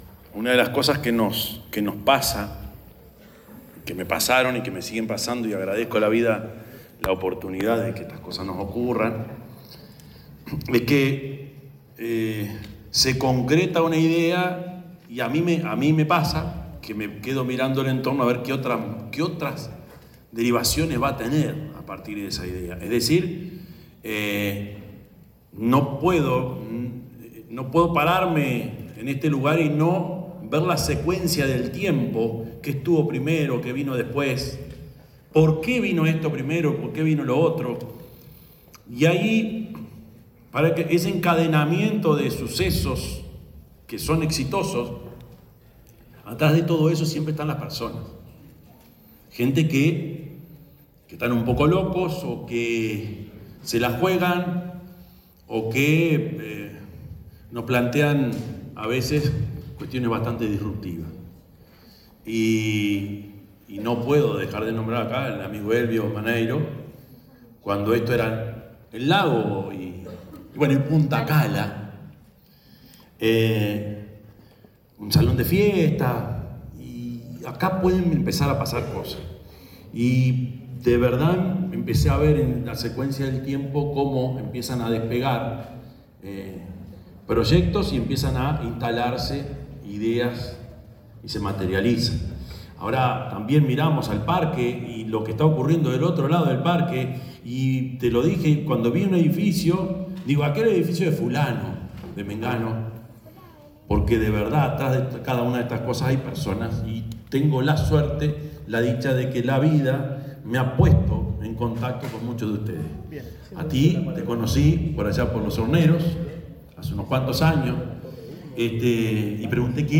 Palabras del presidente de la República, Yamandú Orsi 01/12/2025 Compartir Facebook X Copiar enlace WhatsApp LinkedIn El presidente de la República, Yamandú Orsi, hizo uso de la palabra en la inauguración de la primera torre del complejo Cala del Yacht, parte de lo que será un nuevo núcleo urbano en el este metropolitano. Valoró a quienes apuestan en Uruguay para invertir.